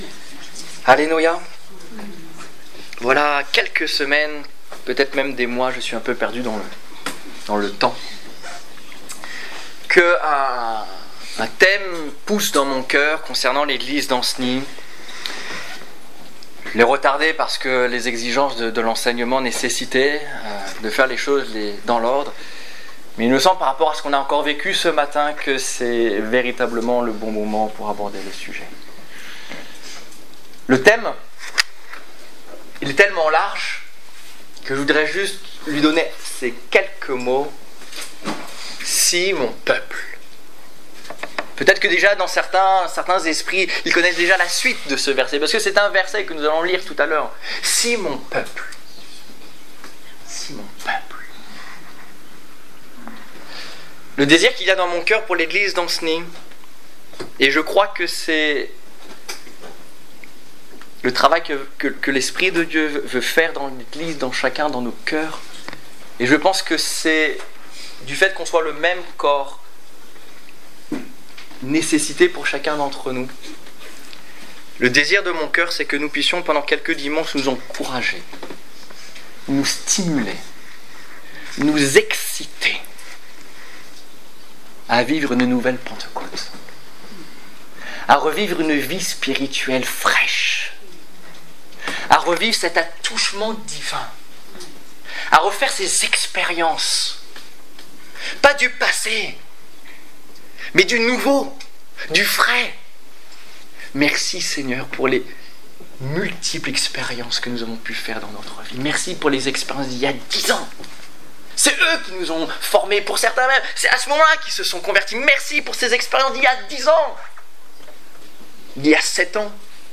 Culte du 2 novembre 2014 Ecoutez l'enregistrement de ce message à l'aide du lecteur